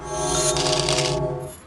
secret_discovered.ogg